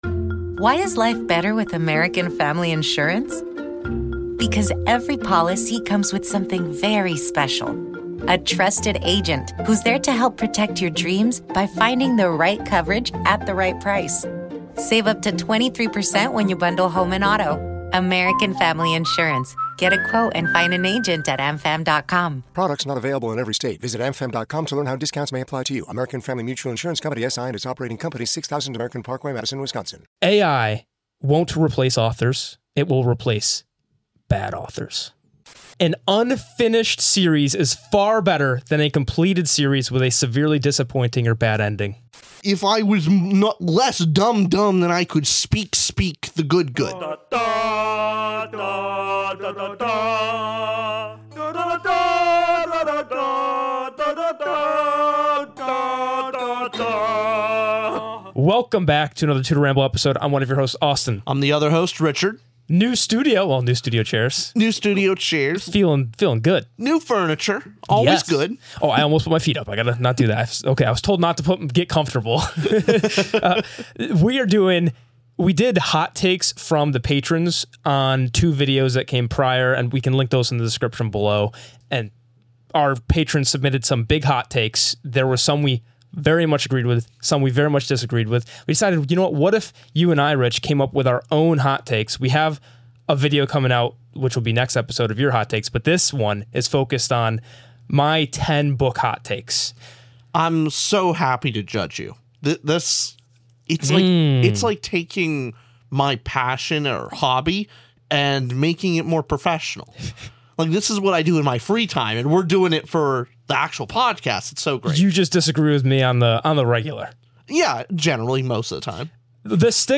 2 guys talking about books (mostly fantasy & sci-fi).